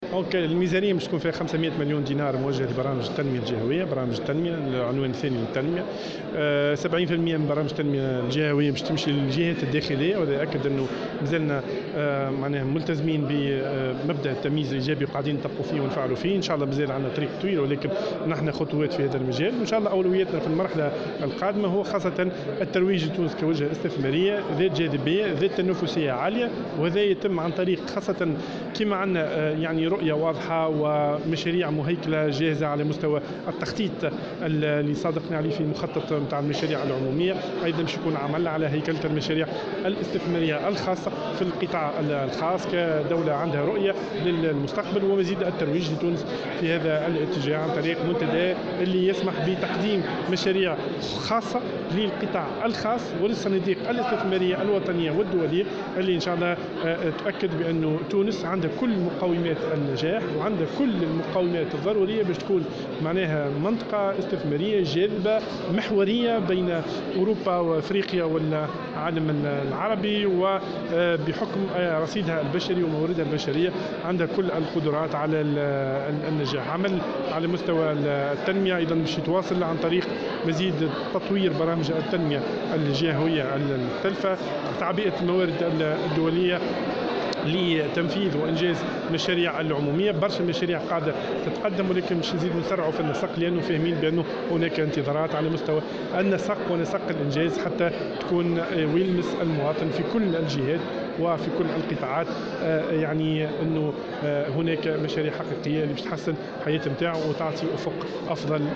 Dans une déclaration accordée à la correspondante de Jawhara FM, en marge de l'adoption du budget du ministère à l'ARP, Ladhari a ajouté que 70% des programmes de développement régional seront dédiés aux régions intérieures, en application du principe de discrimination positive.